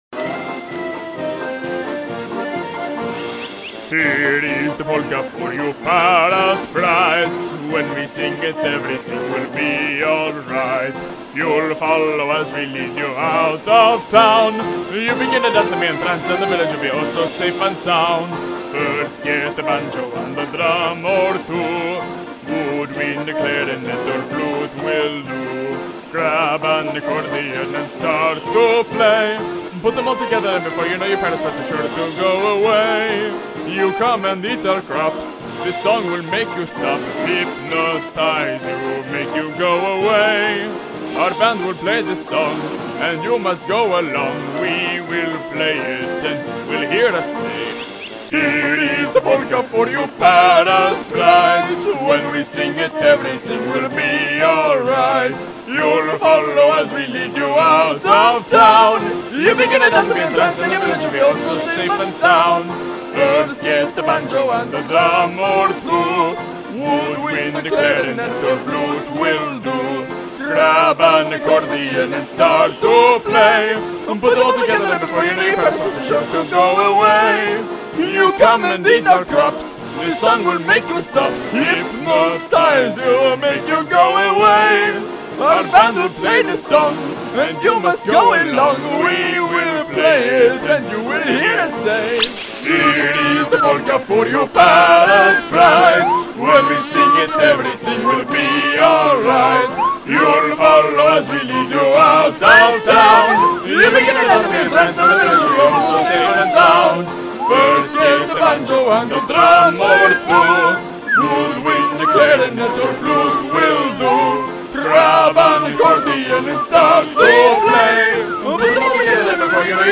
The world always needs more polka. So I added some lyrics.